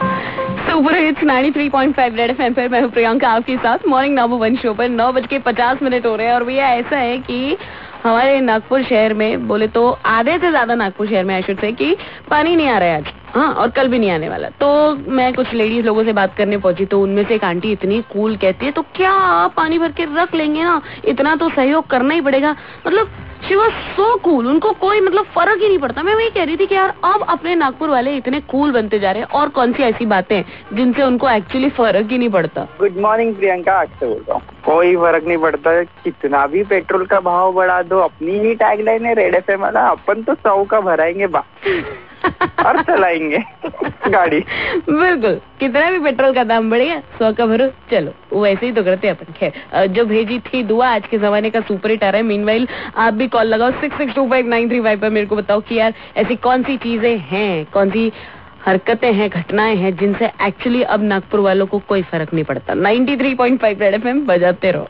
interacting with callers